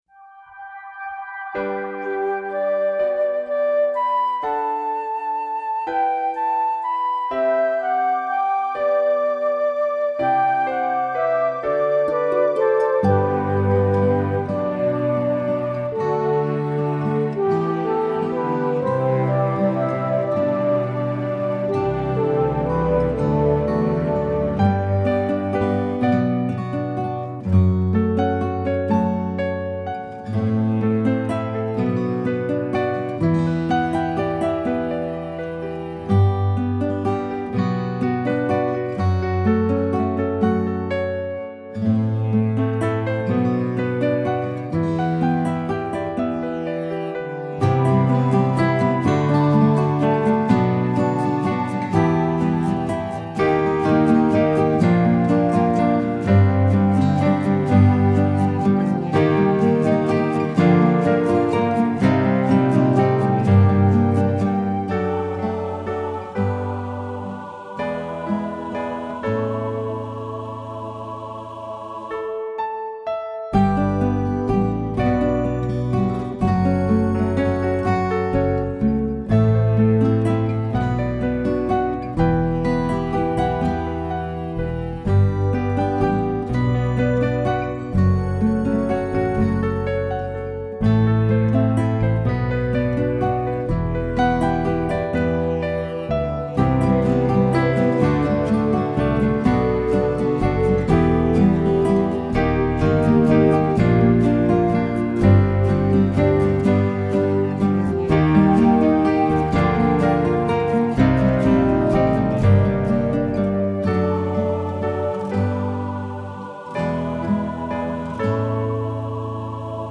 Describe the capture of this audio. Below are some recent guitar mixes I’ve done: